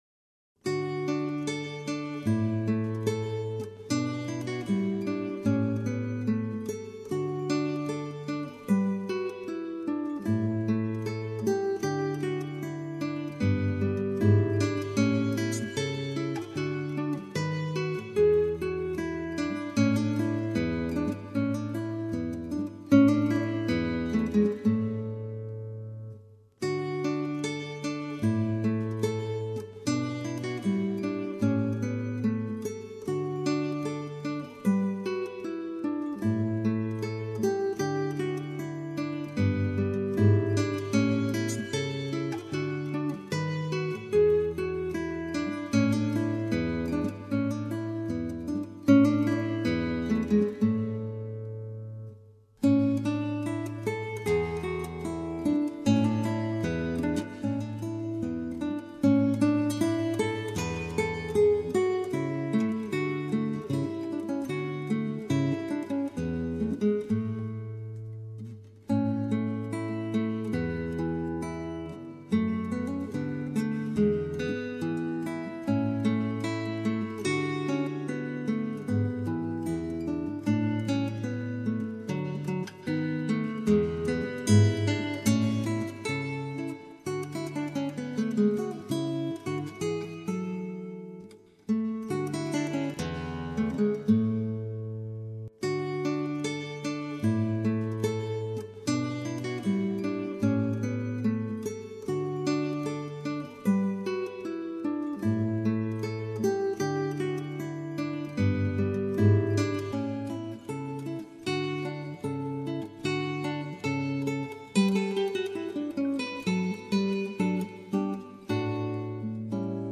Gitaar Solo
• 22 stukken in Barok stijl